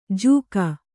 ♪ jūka